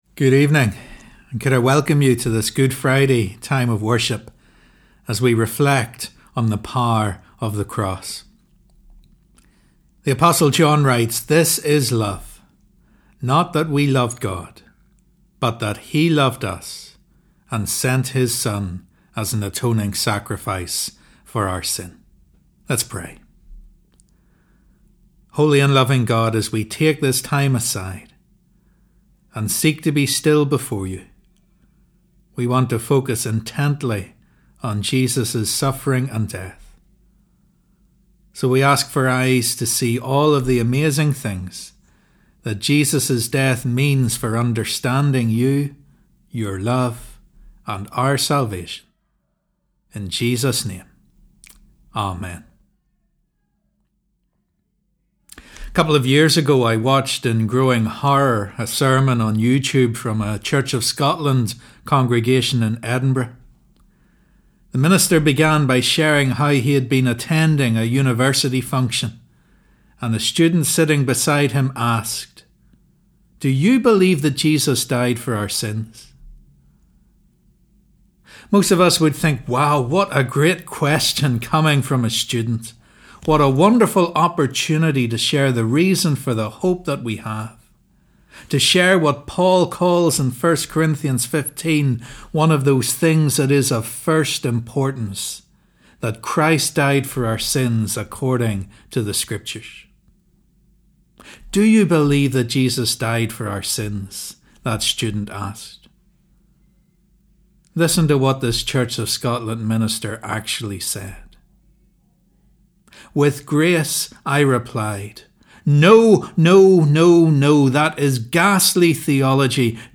Holy Week Reflection: Good Friday